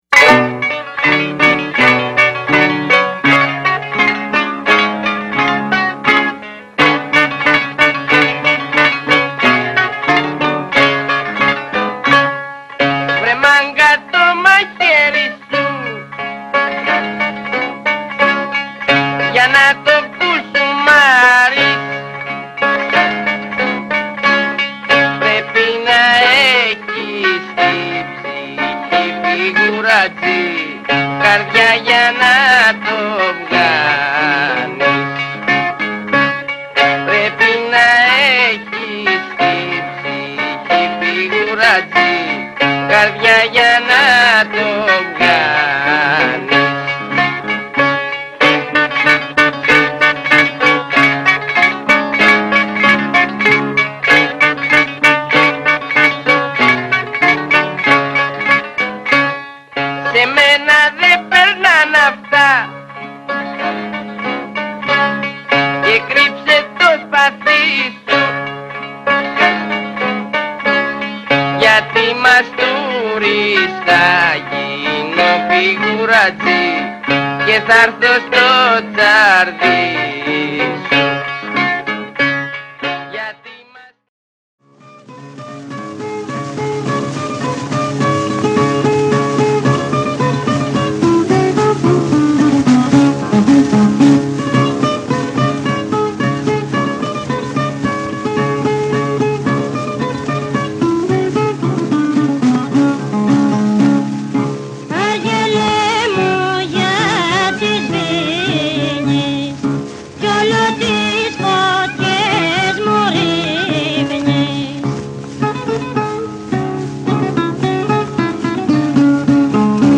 This segment includes some of the music called Rembetico made by Greeks in the 1920s and 30s when they were expelled from Turkey.